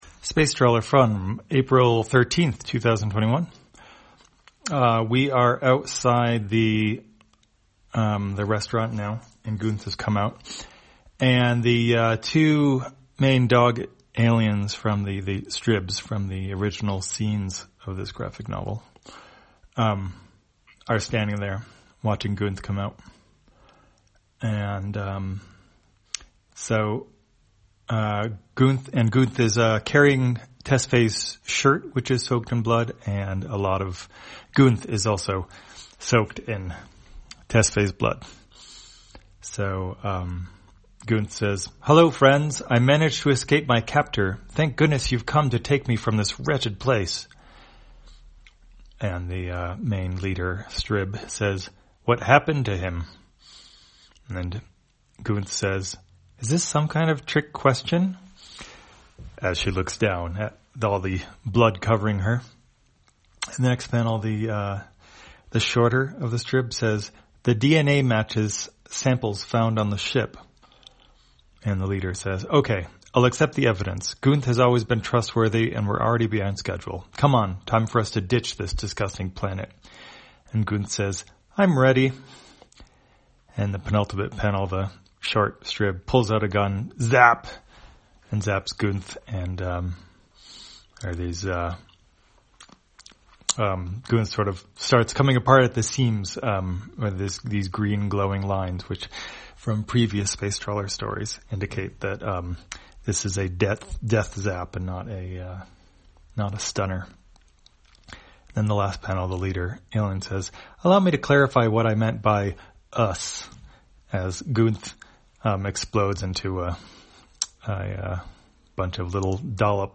Spacetrawler, audio version For the blind or visually impaired, April 13, 2021.